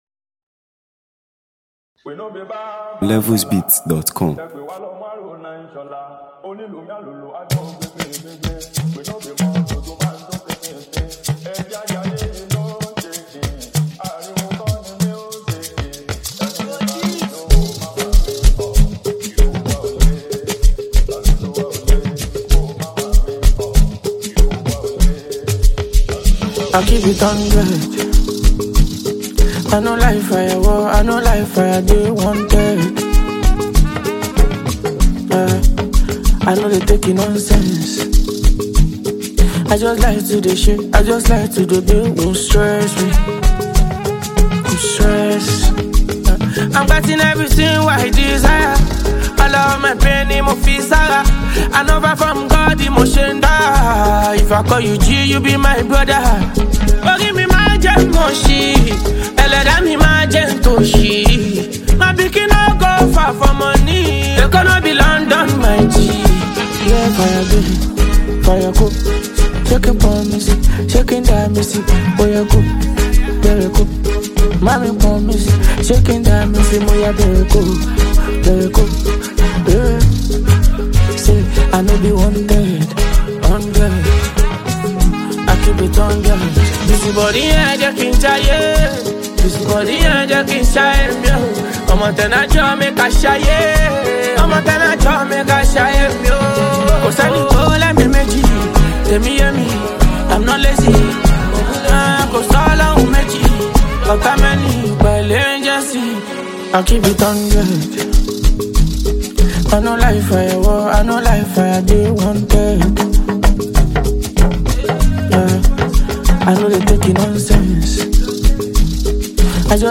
Highly talented Nigerian street-pop sensation and songwriter